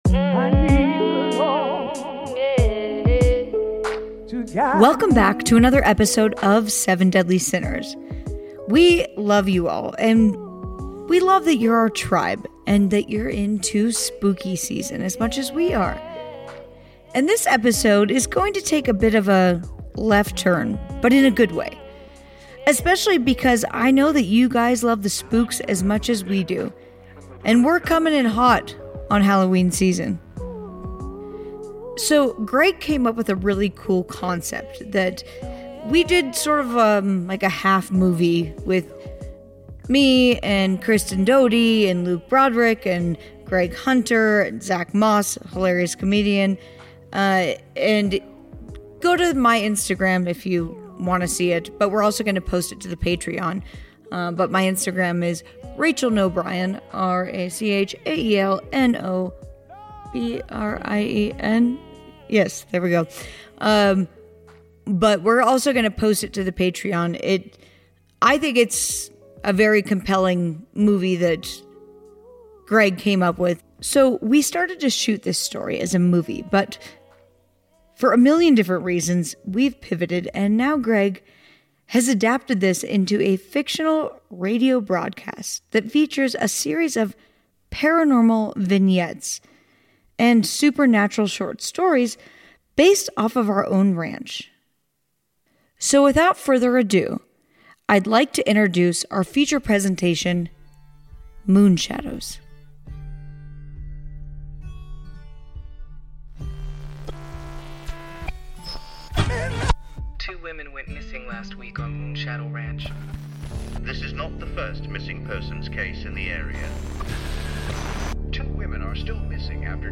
This week we are giving a sneak peak exclusive preview of a fictional radio broadcast from a shipping container radio tower on a ranch in a parallel dimension known as Moonshadow Ranch. This series is an original audio anthology featuring short stories, paranormal vignettes and independent artists perming a number of magic tricks that can be captured with microphones. It's background noise.